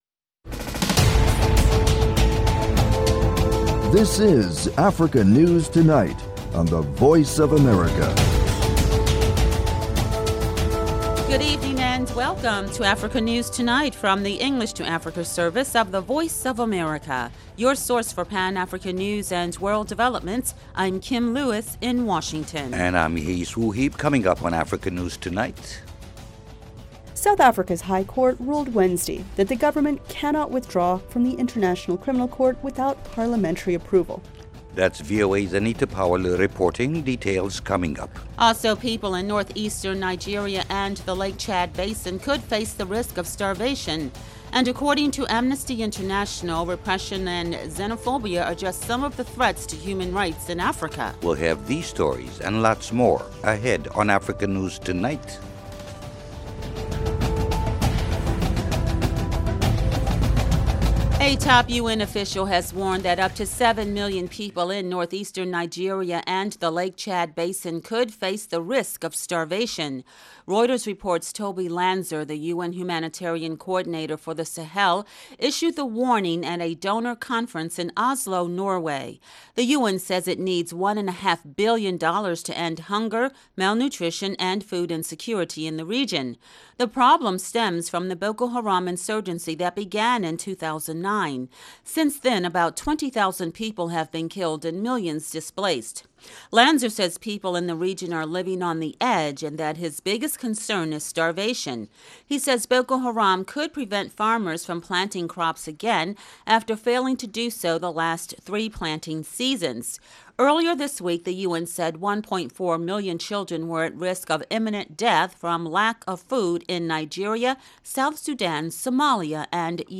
Africa News Tonight is a lively news magazine show featuring VOA correspondent reports, interviews with African officials, opposition leaders, NGOs and human rights activists. News feature stories look at science and technology, environmental issues, humanitarian topics, the African diaspora, business, arts and culture. Music and the popular sports segment, Sonny Side of Sports, round out the show.